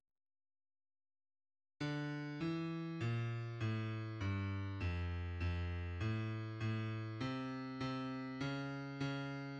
low part